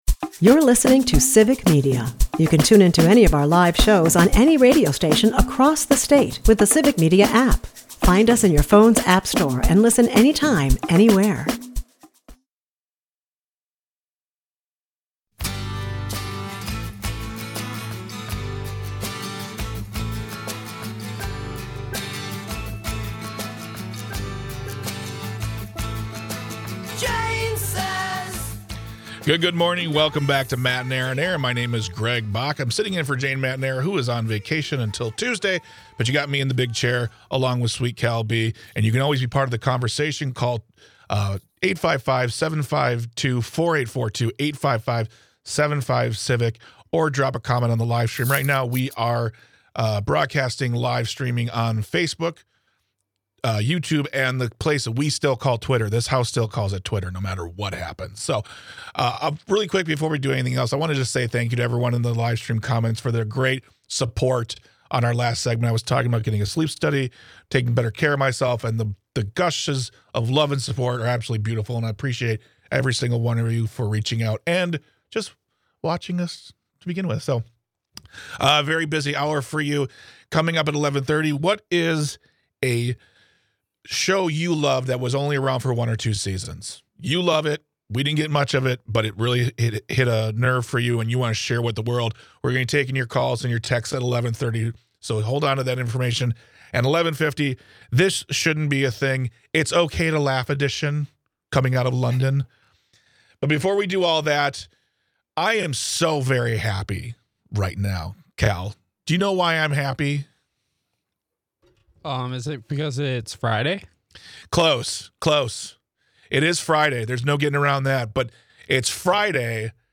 In the second hour, Hari Kondabolu is our guest.
Matenaer On Air is a part of the Civic Media radio network and airs Monday through Friday from 10 am - noon across the state.